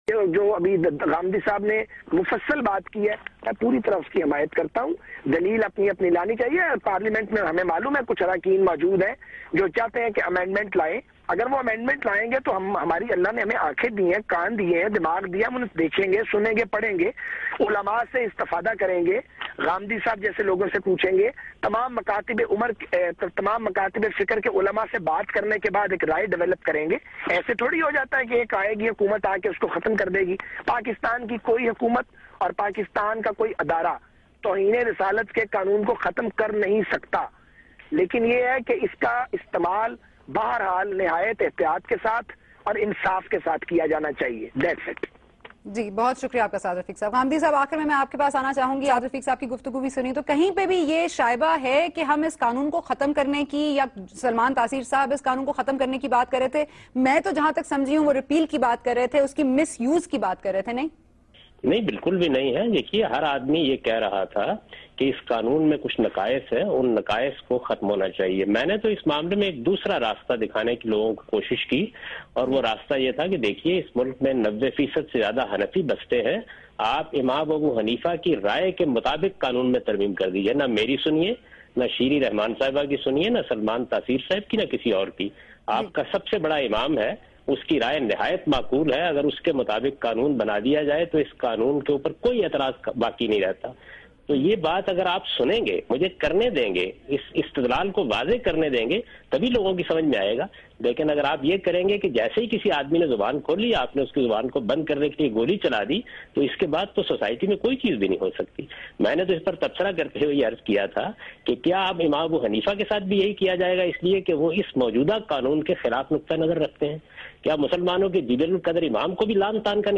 Category: TV Programs / Geo Tv / Questions_Answers /
Javed Ghamidi & Saad Rafique on salman taseer's killing on Geo Tv with Sana Bucha.